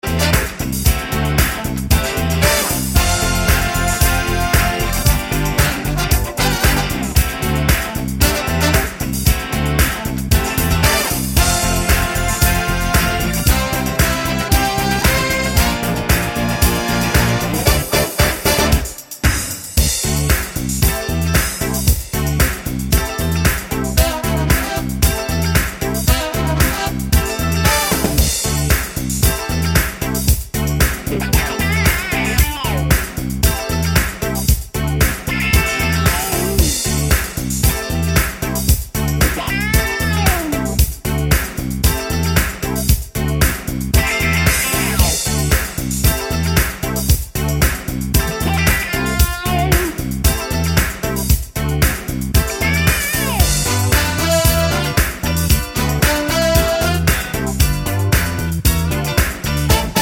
no Backing Vocals Soul / Motown 4:09 Buy £1.50